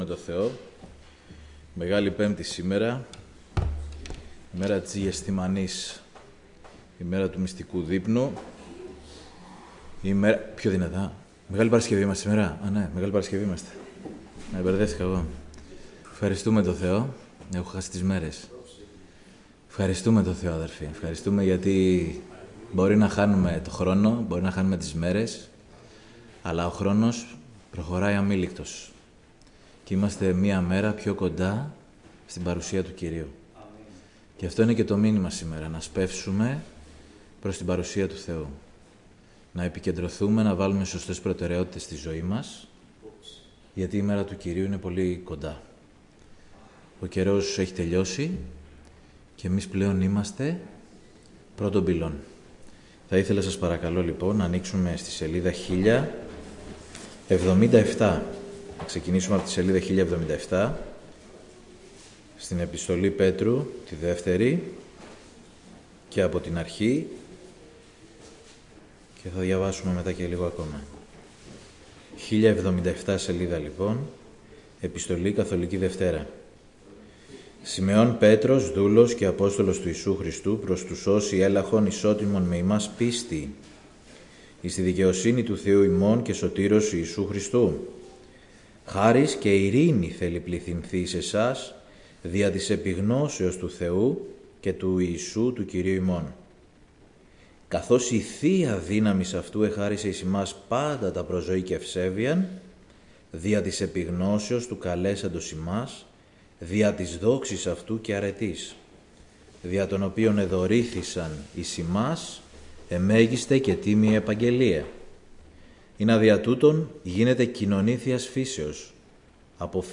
Σειρά: Κηρύγματα